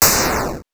8 bits Elements
explosion_14.wav